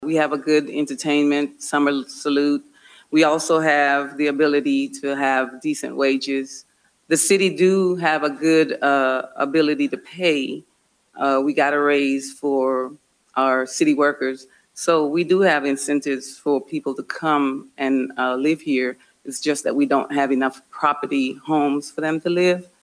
The League of Women Voters hosted the candidates Monday night for its final forum of the night.